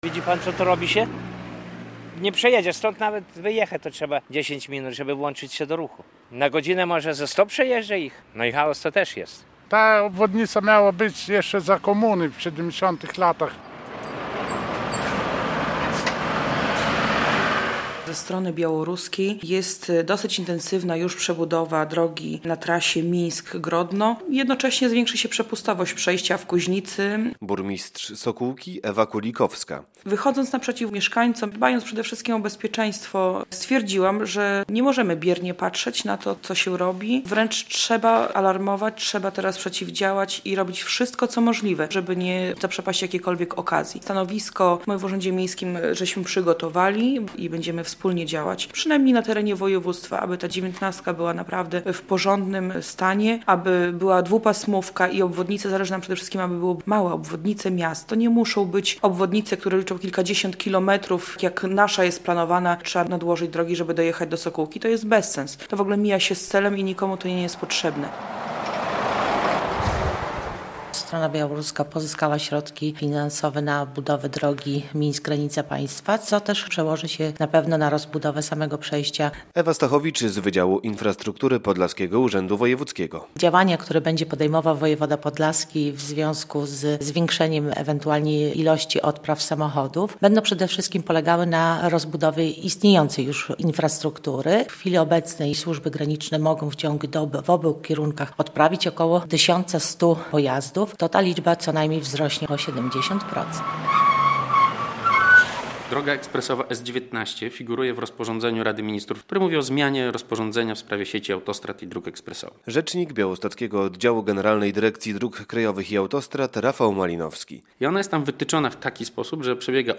Mieszkańcy Sokółki domagają się budowy obwodnicy - relacja